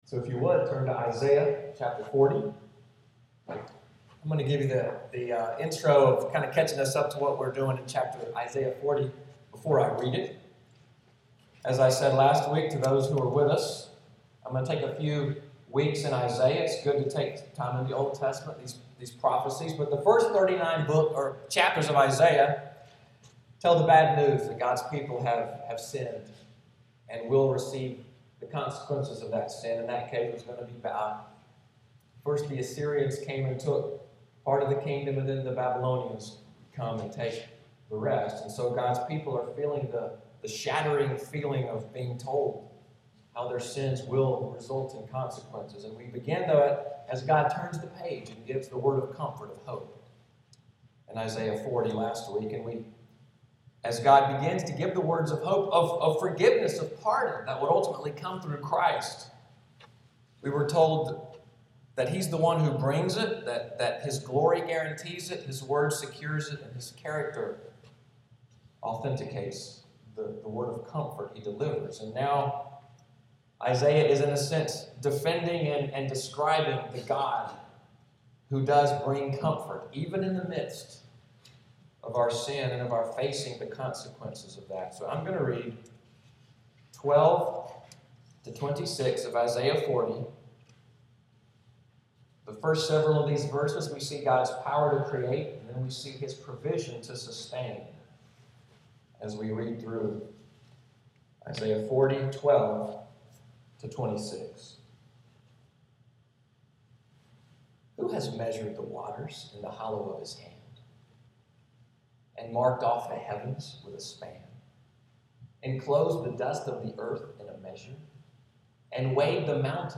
Sermon outline & Order of worship